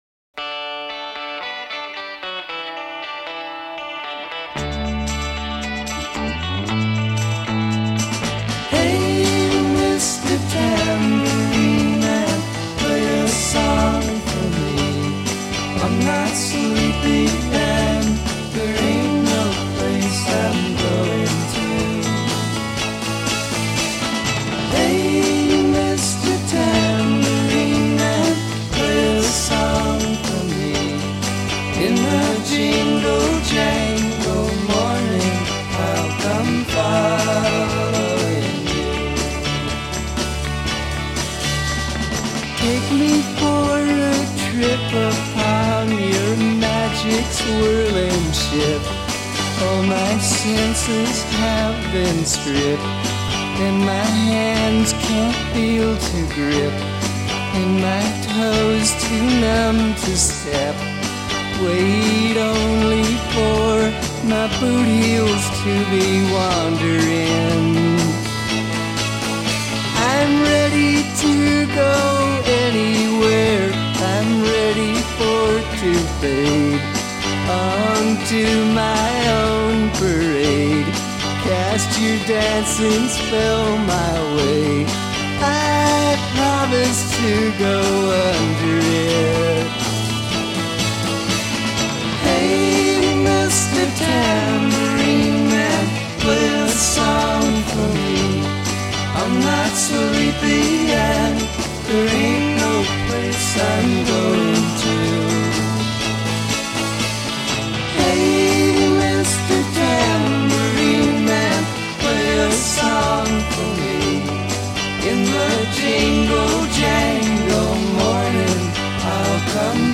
wide remix.
It fades out sooner, I think.